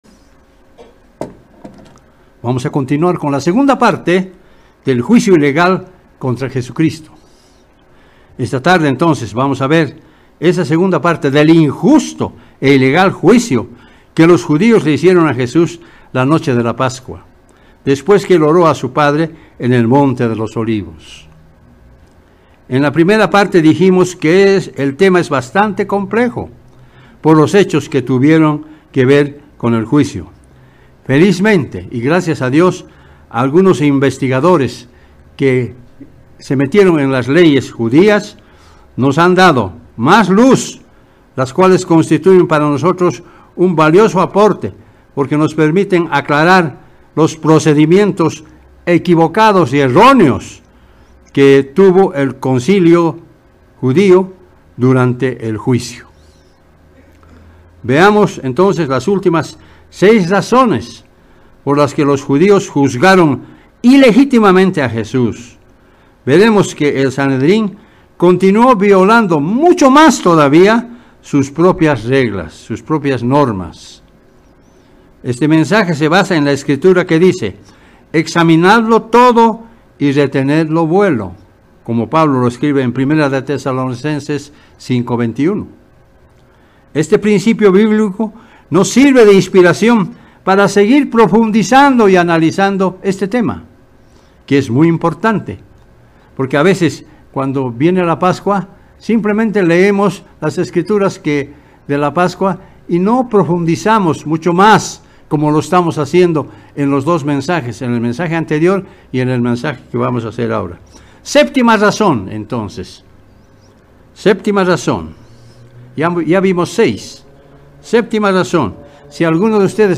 La condena y crucifixión de Jesús, fueron parte de un torcido proceso legal... sin embargo, ha sido para salvación de la humanidad entera. Mensaje entregado el 6 de abril de 2023.